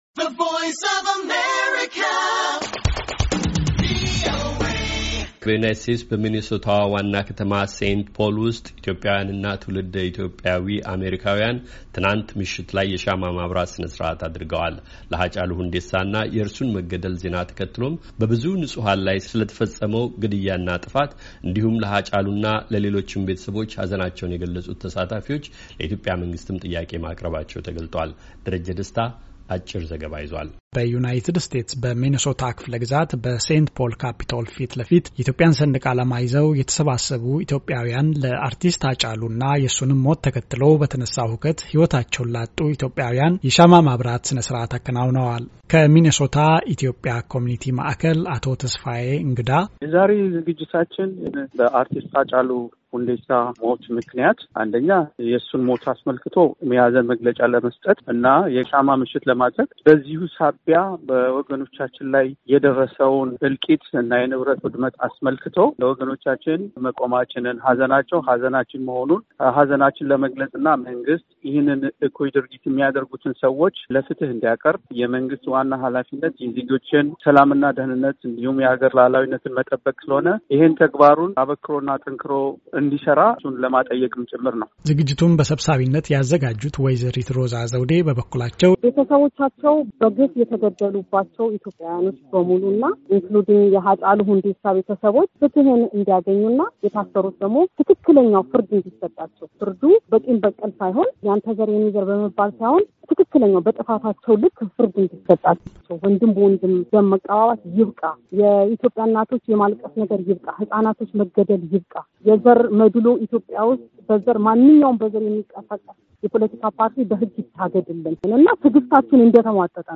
ሚኔሶታ ዋና ከተማ ሴንት ፖል ውስጥ ኢትዮጵያዊያንና ትውልደ ኢትዮጵያ አሜሪካዊያን ትናንት ዓርብ፤ ሐምሌ 3/2012 ዓ.ም. ምሽት ላይ ሻማ የማብራት ሥነ ሥርዓት አድርገዋል።